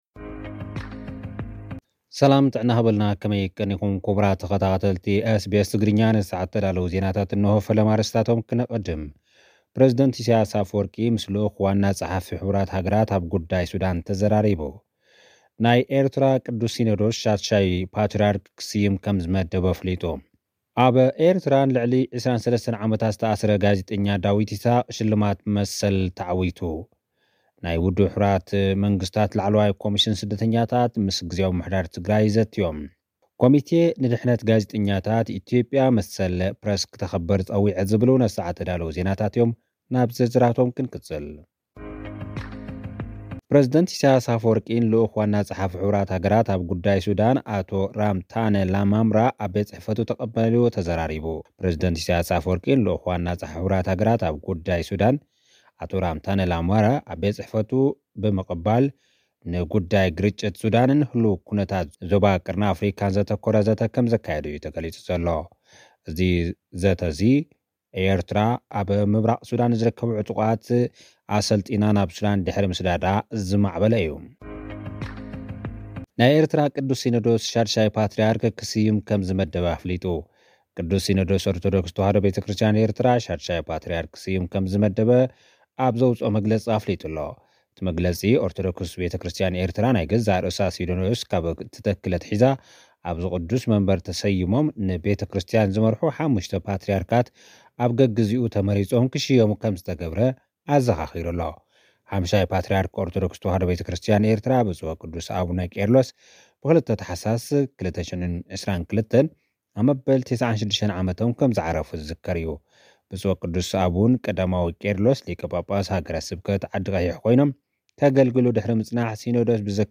ልኡኽና ዝሰደደልና ጸብጻብ እዞም ዝስዕቡ ኣርእስታት ኣለዉዎ፡ ፕረዚደንት ኢሰይያስ ኣፈዎርቂ፡ ንልኡኽ ዋና ጸሓፊ ሕቡራት ሃገራት ኣብ ጉዳይ ሱዳን ኣዘራሪቡ። ናይ ውድብ ሕቡራት መንግስታት ላዕለዋይ ኮሚሽን ስደተኛታት ምስ ጊዝያዊ ምምሕዳር ትግራይ ዘትዮም።ኮሚተ ንድሕነት ጋዜጠኛታት፡ ኢትዮጵያ መሰል ፕረስ ከተኽብር ጸዊዑ።